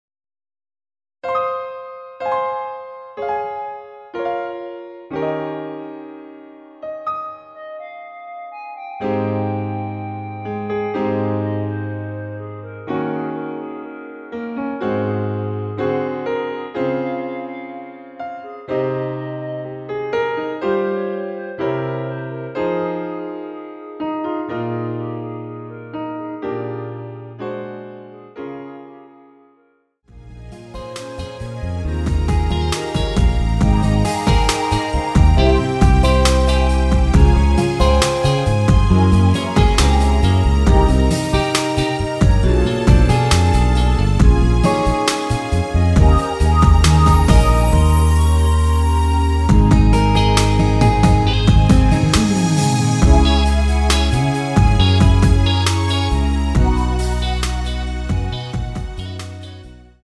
원키에서(+3)올린 멜로디 포함된 MR입니다.
Ab
노래방에서 노래를 부르실때 노래 부분에 가이드 멜로디가 따라 나와서
앞부분30초, 뒷부분30초씩 편집해서 올려 드리고 있습니다.
중간에 음이 끈어지고 다시 나오는 이유는